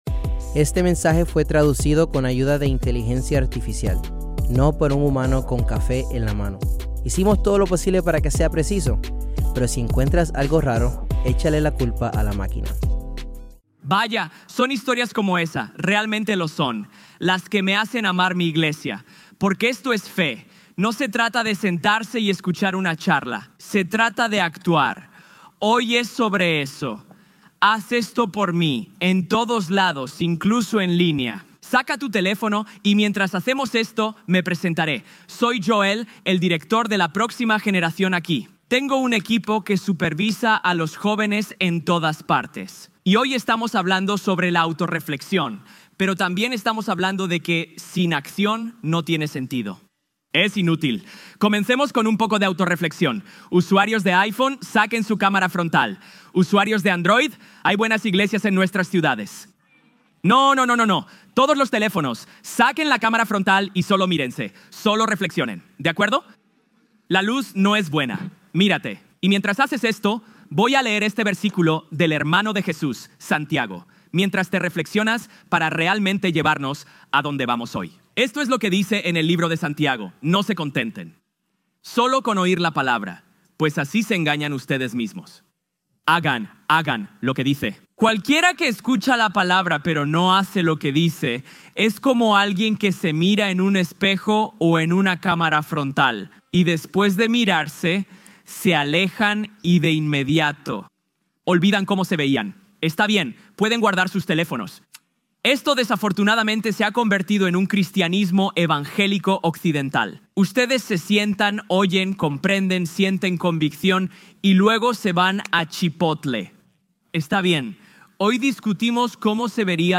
Cuando reconocemos y nos arrepentimos, Jesús puede redimirnos. Grabado en vivo en Crossroads Church en Cincinnati, Ohio.